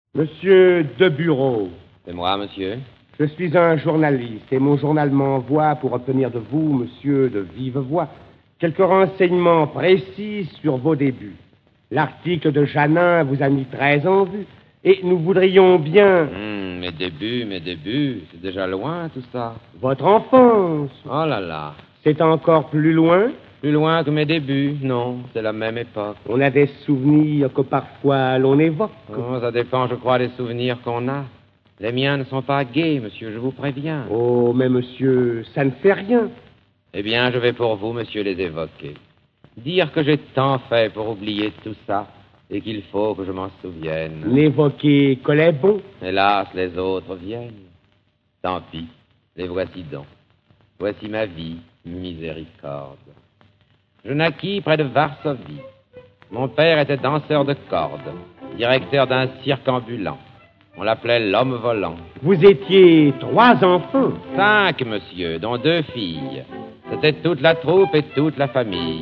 Lire un extrait Sacha Guitry Les femmes et l'amour Compagnie du Savoir Date de publication : 2010 Extraits de « Les femmes et l'amour » : conférence faite au Théâtre des Variétés en 1934 par Sacha Guitry.
Enregistrement original 4 pistes